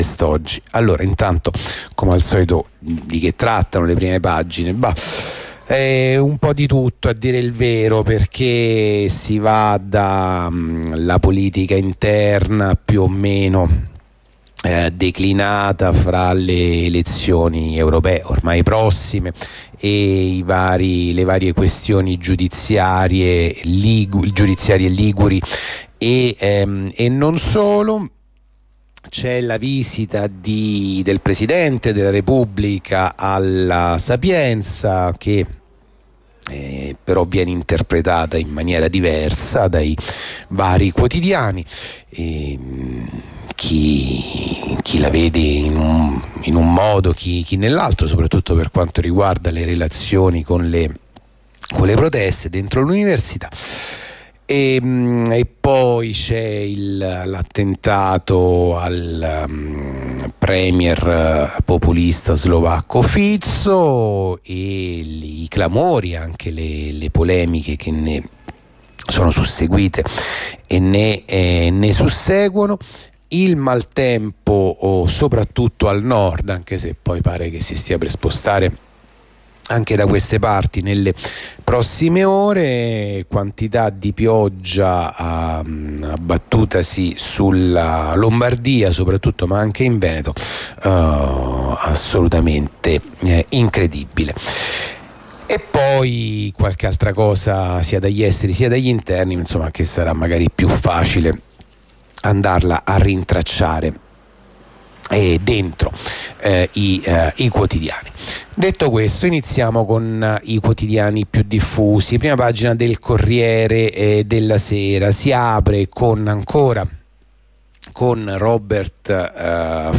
La rassegna stampa di radio onda rossa andata in onda venerdì 17 maggio 2024